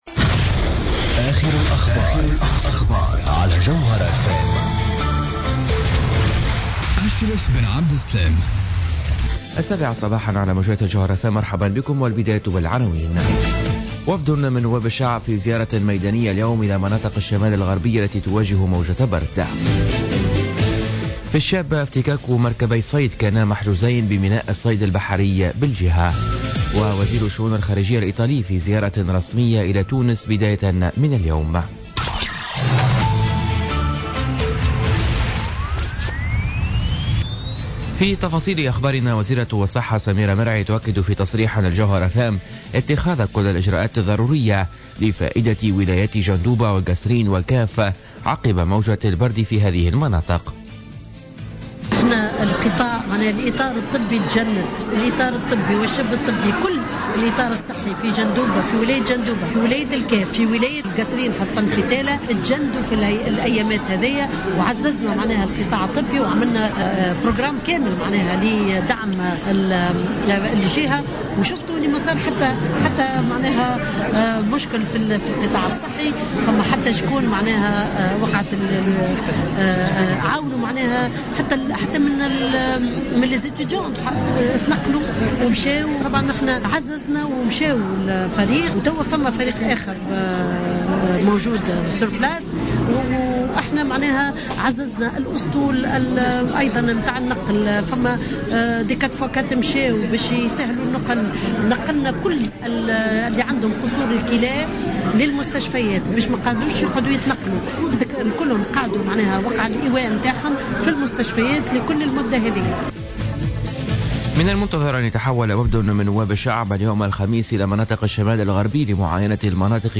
Journal Info 07h00 du jeudi 19 janvier 2017